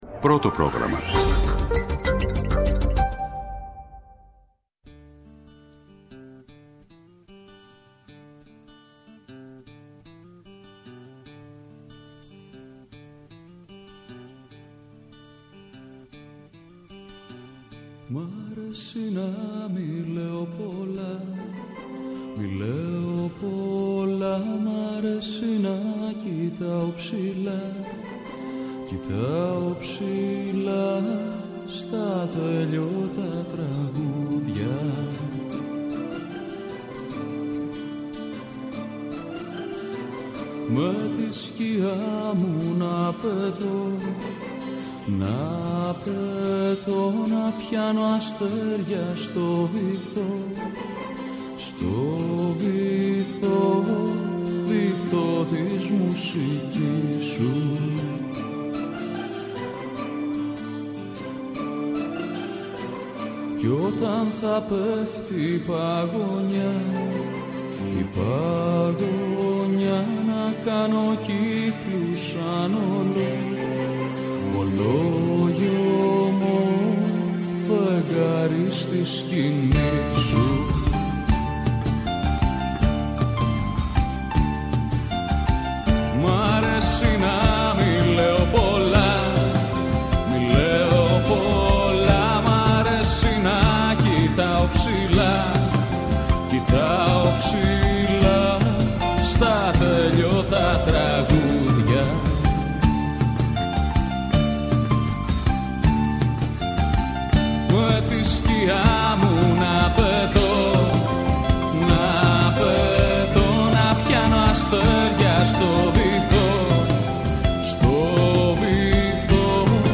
Τα θέματα που μας απασχόλησαν, μέσα από ηχητικά αποσπάσματα, αλλά και συνεντεύξεις.